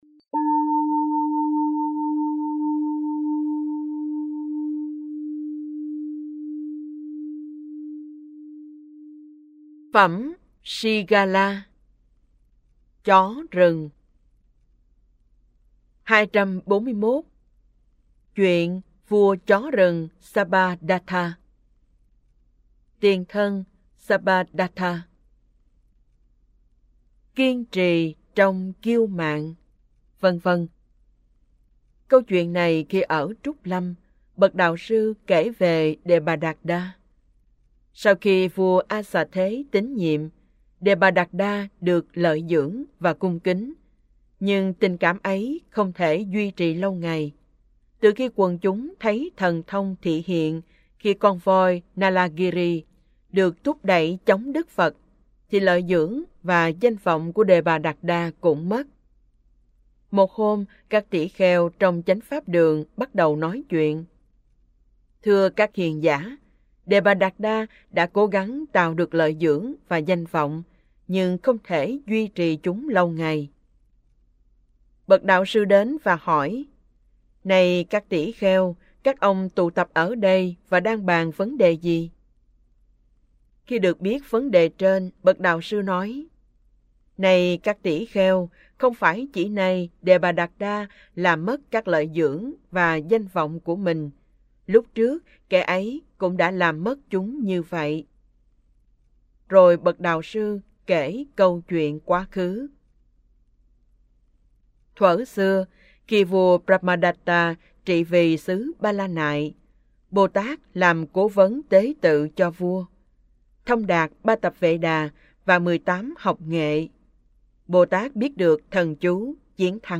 Kinh Tieu Bo 4 - Giong Mien Nam